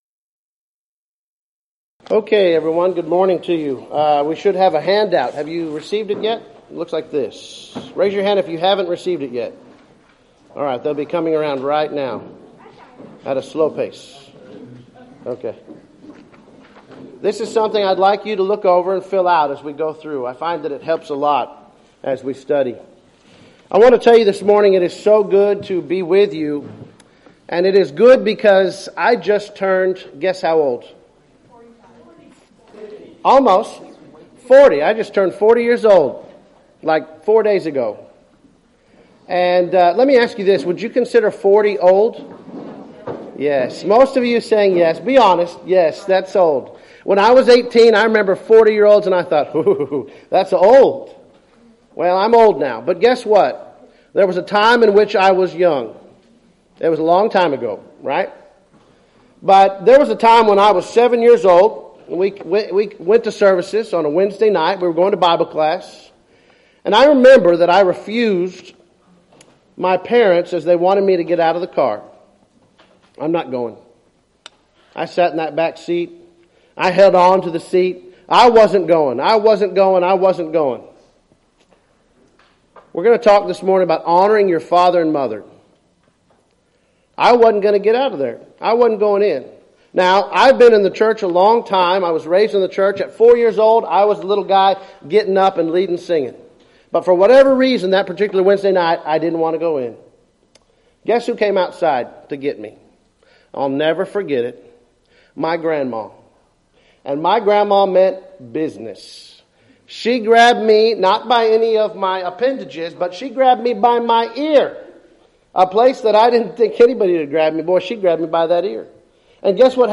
Event: 3rd Annual Southwest Spritual Growth Workshop
Youth Sessions
lecture